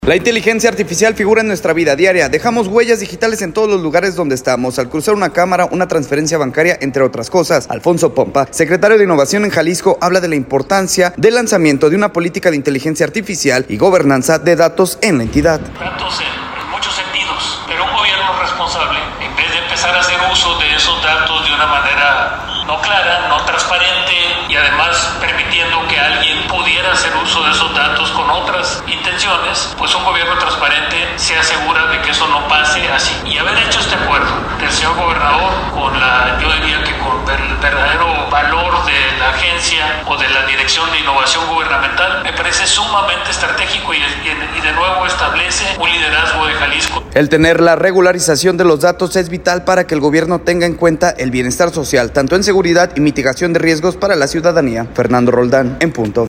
Alfonso Pompa, secretario de Innovación en Jalisco habla de la importancia del lanzamiento de una política de inteligencia artificial y gobernanza nos dio datos en la entidad.